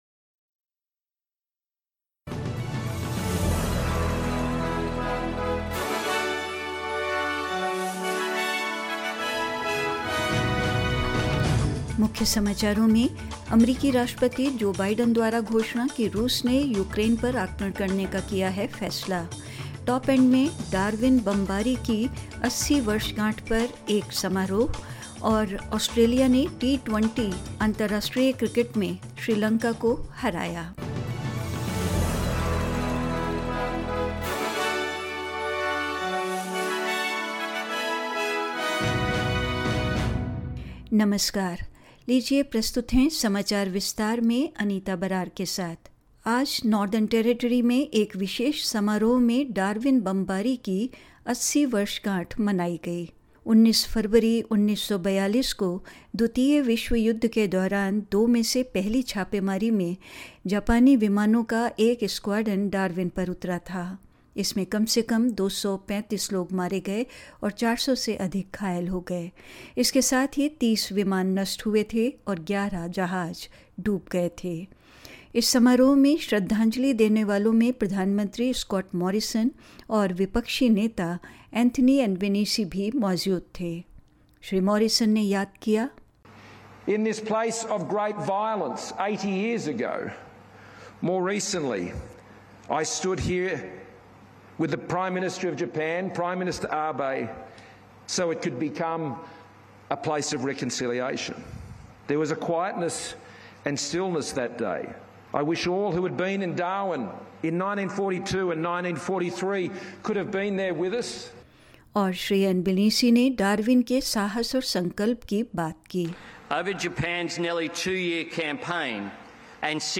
In this latest SBS Hindi bulletin: US President Joe Biden declares Russia's leader has decided to invade Ukraine; The 80th anniversary of the Darwin bombing is commemorated at a ceremony in the Top End; Australia claims a Friday night win against Sri Lanka in the Twenty20 international cricket and more news.